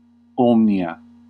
Ääntäminen
US : IPA : /hoʊl/